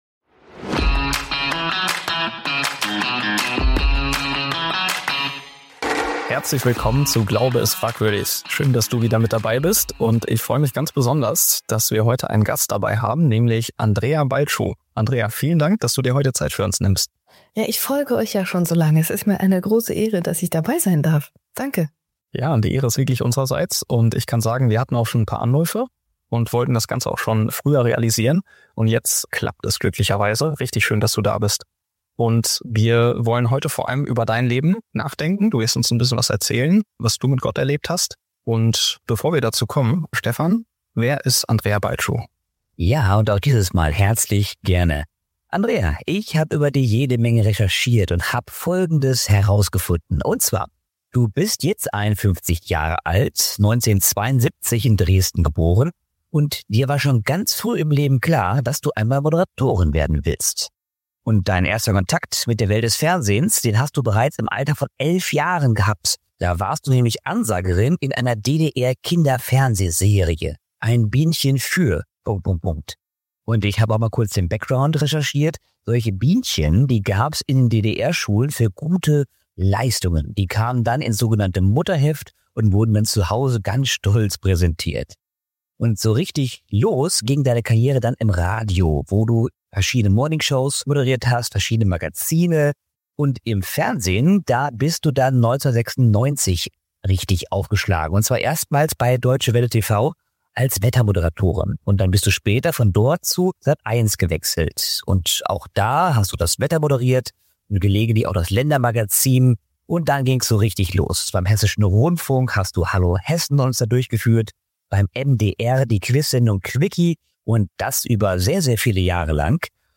Vom TV-Star zur Christin: Was mein Leben komplett verändert hat - Ein Gespräch mit Andrea Ballschuh (Fernseh- und Radiomoderatorin und Videocoach) ~ Glaube ist frag-würdig Podcast